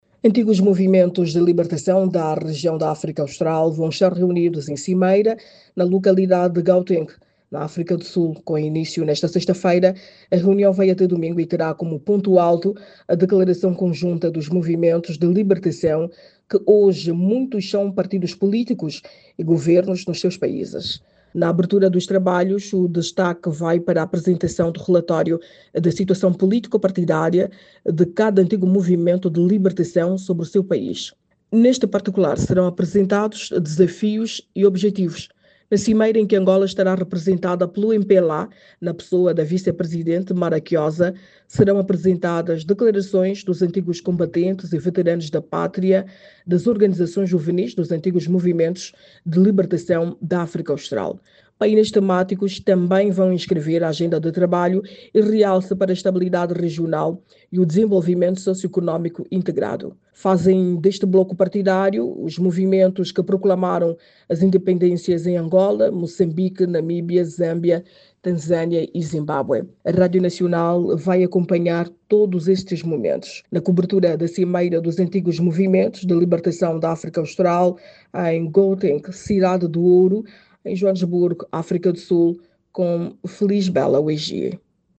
a partir de Joanesburgo.